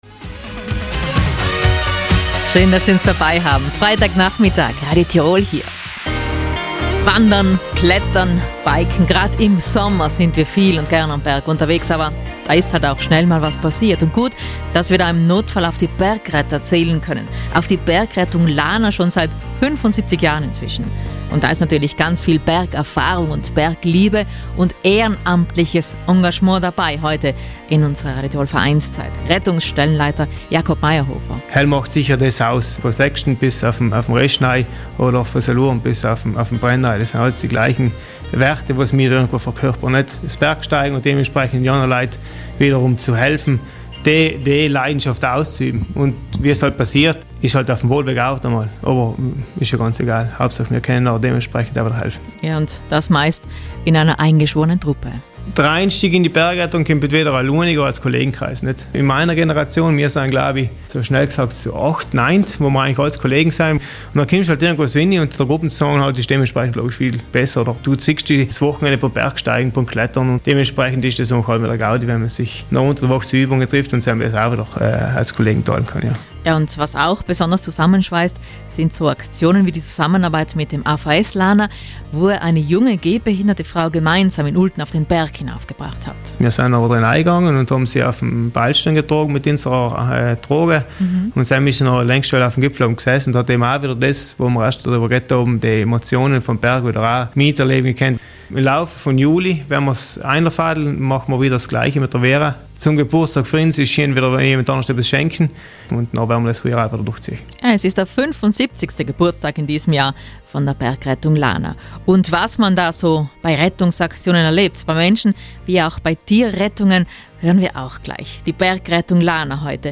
Hier noch mehr Infos zur Bergrettung Lana. zurück 1 /1 vor Audiobeitrag anhören stopp 00:00 / 03:43 Ihr Webbrowser kann den Audiobeitrag leider nicht direkt abspielen. zurück drucken